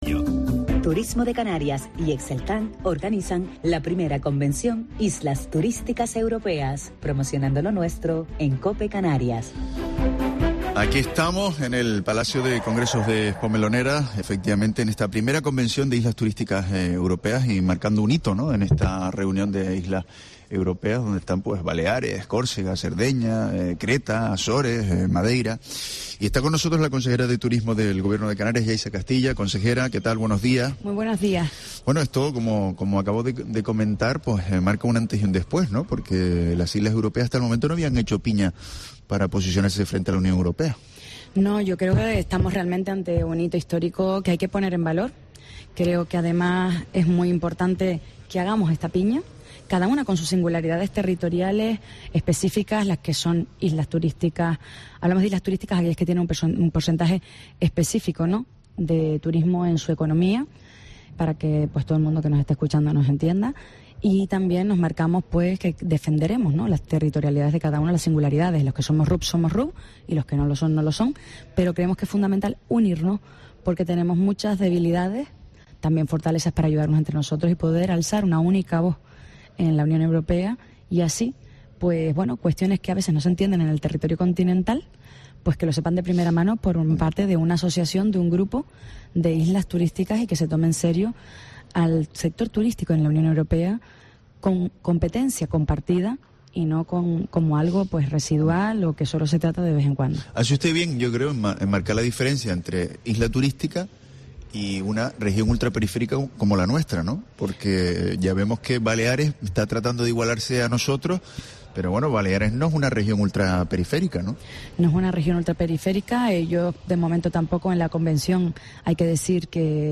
Yaiza Castilla, consejera de Turismo del Gobierno de Canarias